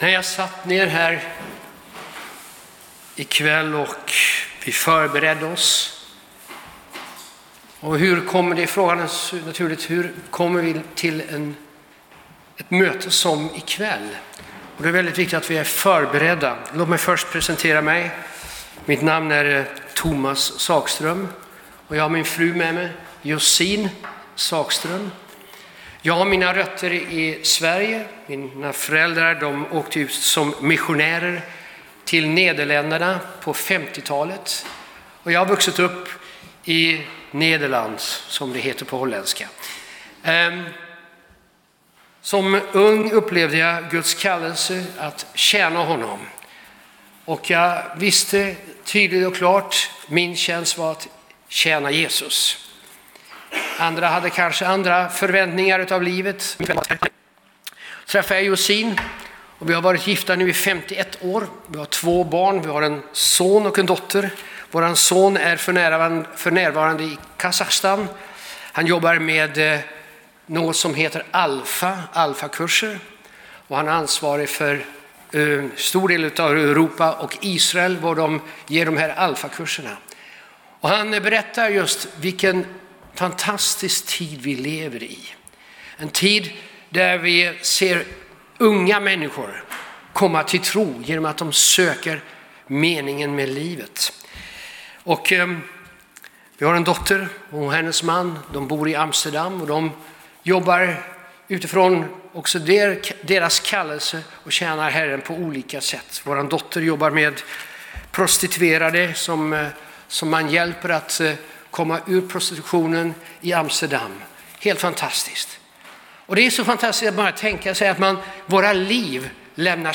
Det finns ett par ikoner uppe till höger där du kan lyssna på ”bara” predikan genom att klicka på hörlurarna eller ladda ner den genom att klicka på pilen.